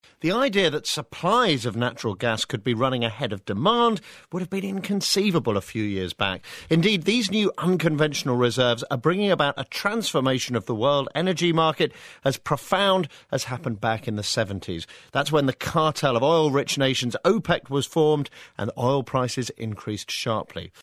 【英音模仿秀】能源市场将有大改革 听力文件下载—在线英语听力室